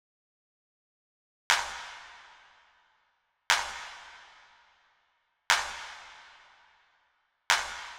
23 Clap.wav